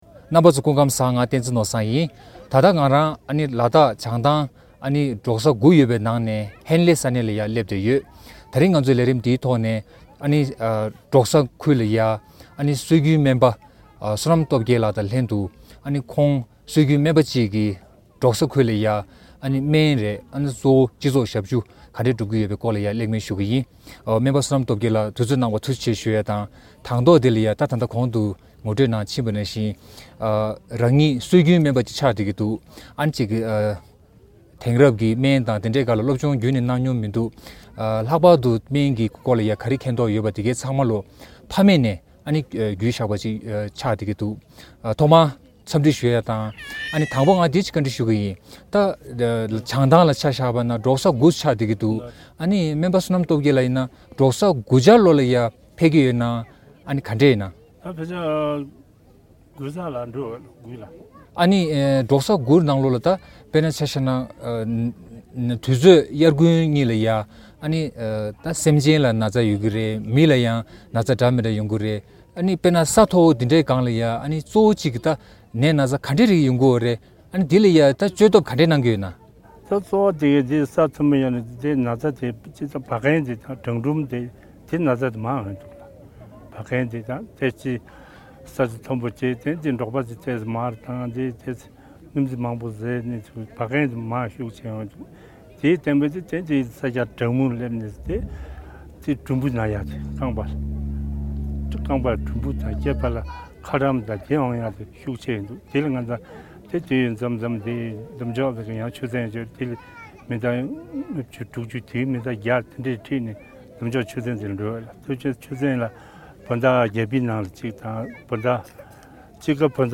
བཅར་འདྲི་ཞུས་ཡོད།